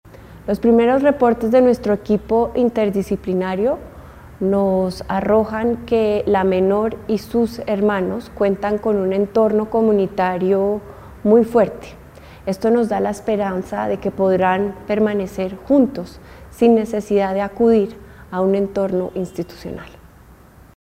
La Directora General del ICBF, Juliana Pungiluppi, resalta que la niña y sus hermanos permanecerán junto a su familia y no en un entorno institucional.